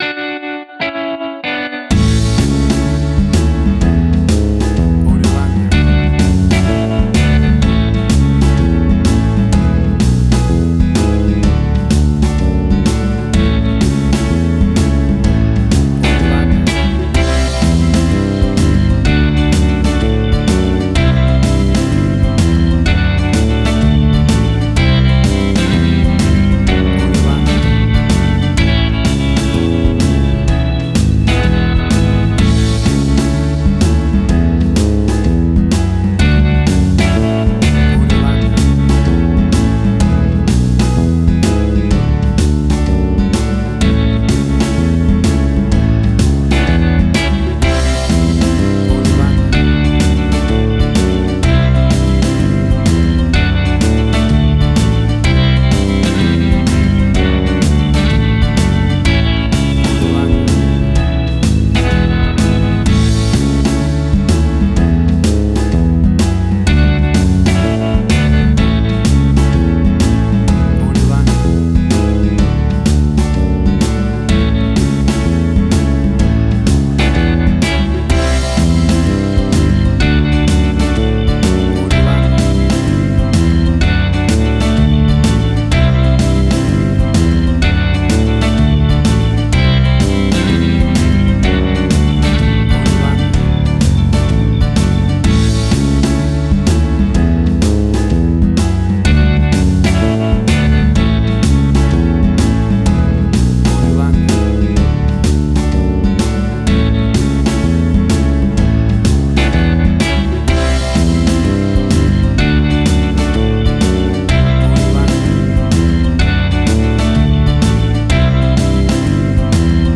WAV Sample Rate: 16-Bit stereo, 44.1 kHz
Tempo (BPM): 63